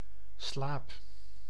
Ääntäminen
Ääntäminen France: IPA: [tɑ̃p] Haettu sana löytyi näillä lähdekielillä: ranska Käännös Konteksti Ääninäyte Substantiivit 1. slaap {m} anatomia Suku: f .